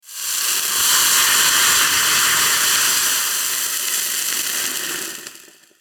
sizzle-mp3.mp3